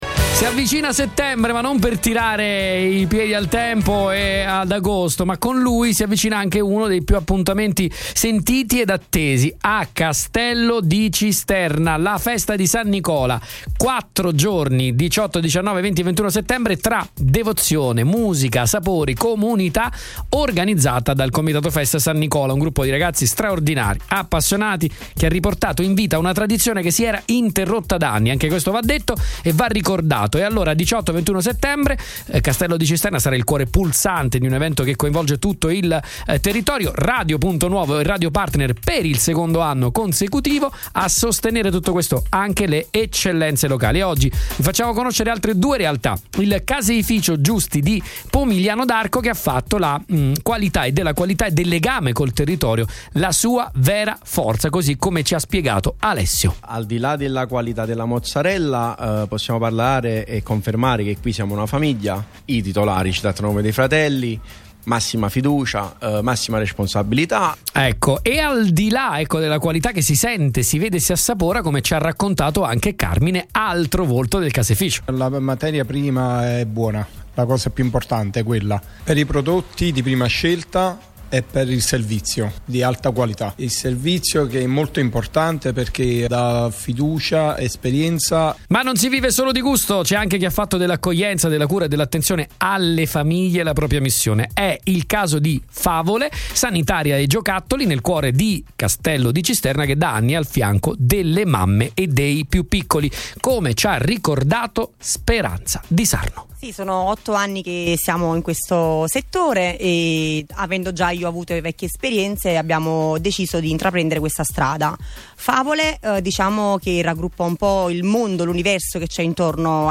Oggi vi portiamo un altro pezzo di anima della festa dando voce a due eccellenze del territorio: il Caseificio Giusti di Pomigliano d’Arco e Favole – Sanitaria e Giocattoli di Castello di Cisterna, entrambi sponsor ufficiali dell’evento.